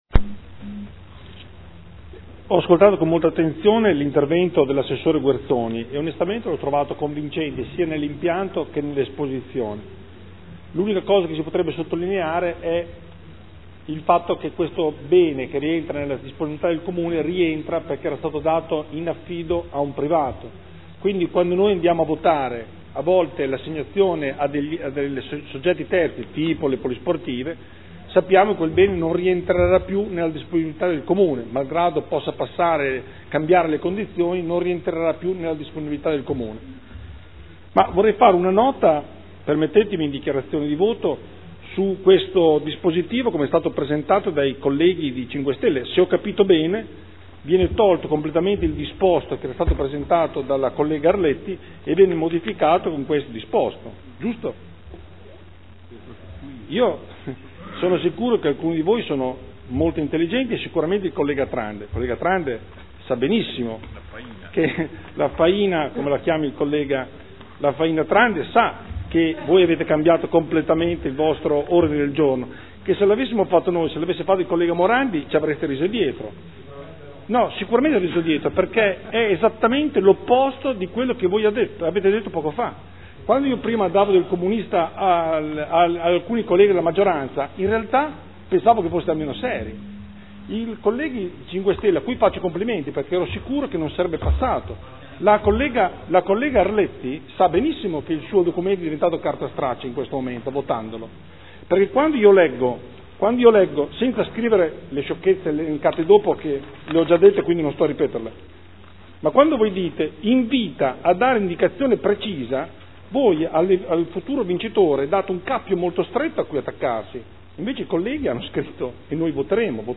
Andrea Galli — Sito Audio Consiglio Comunale
Seduta del 30/04/2015 Dichiarazione di voto. Approvazione indirizzi per l’assegnazione in diritto di superficie di un’area sportiva in via Cassiani